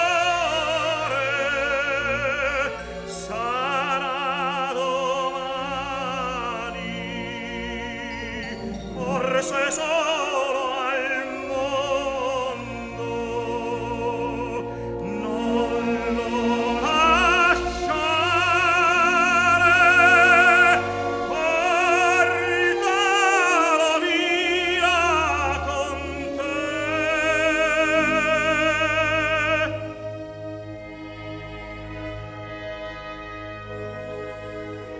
Classical Opera
Жанр: Классика